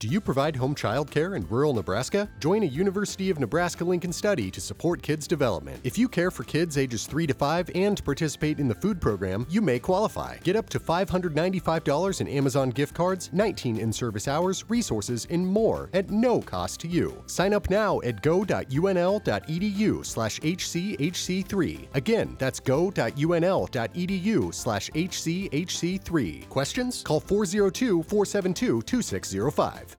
Listen to Radio Spot